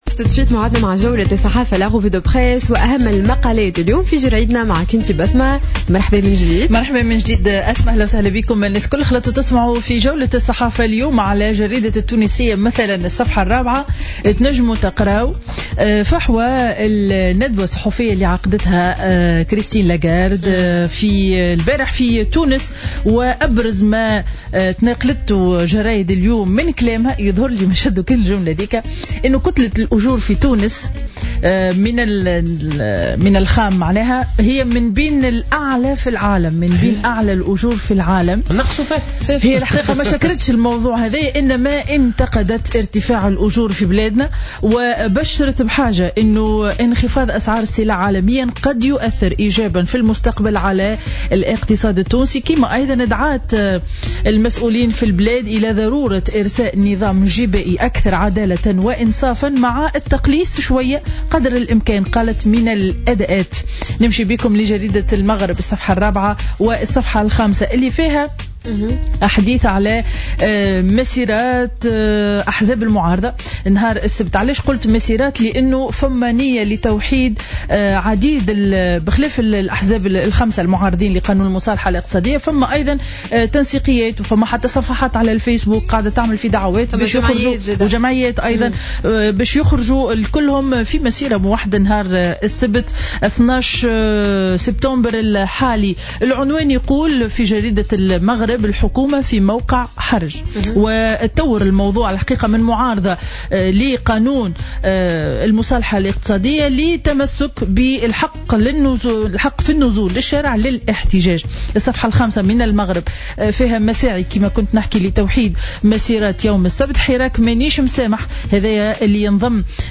Revue de presse du jeudi 10 septembre 2015